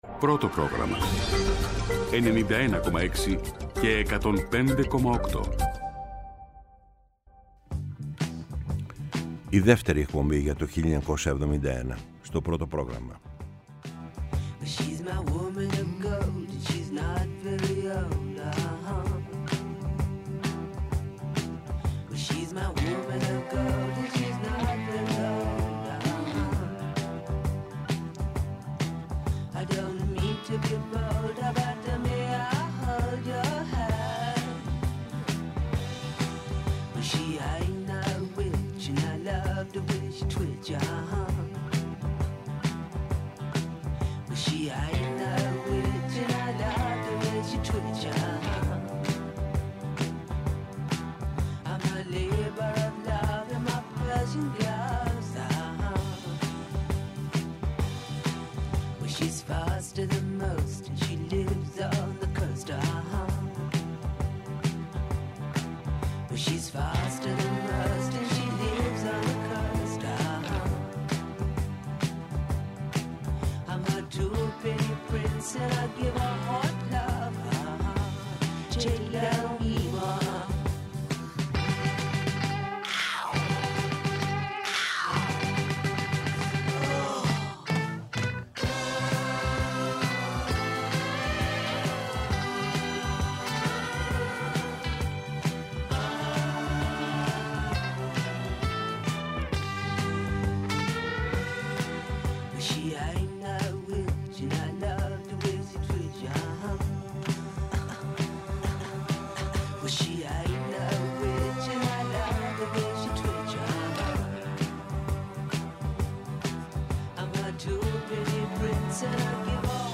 Η μακροβιότερη εκπομπή στο Ελληνικό Ραδιόφωνο!